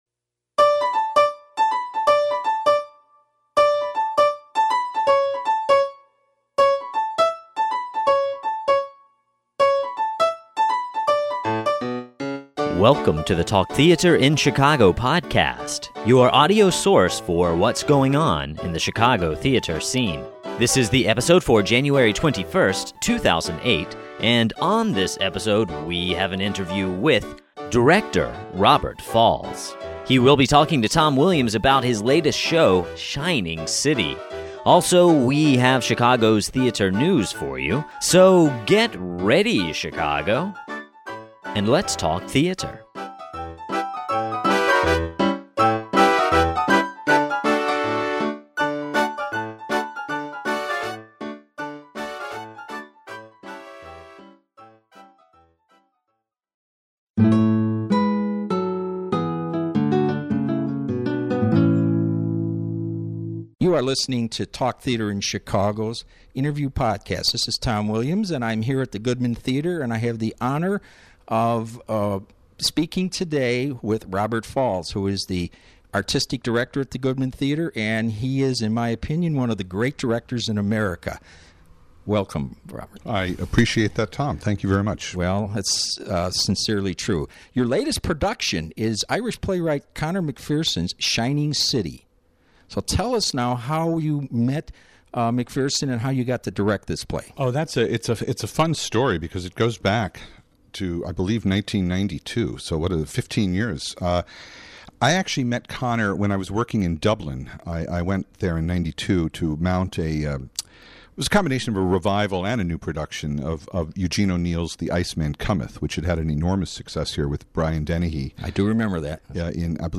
Robert Falls Interview Podcast
An Interview with Robert Falls, the Artistic Director of the Goodman Theatre. He discusses his current play Shining City as well as his career as a director.